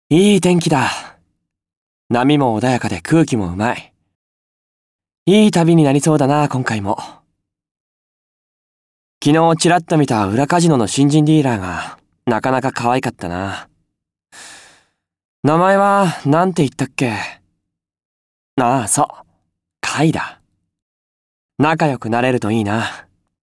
マイケル　　　ＣＶ：野島裕史